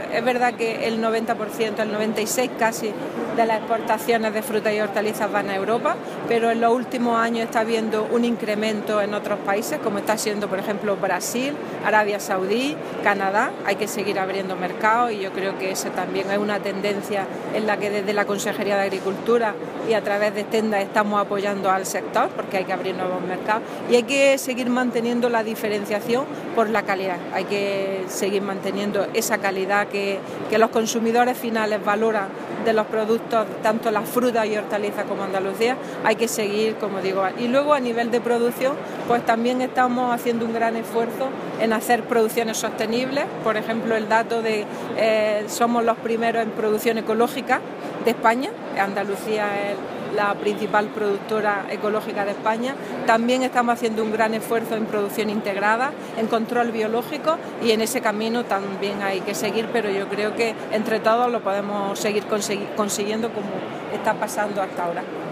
La consejera de Agricultura ha asistido a la inauguración de Fruit Attraction 2016, donde Andalucía es la única Comunidad con un pabellón completo
Declaraciones de Carmen Ortiz sobre la presencia andaluza en Fruit Attraction 2016 (continuación)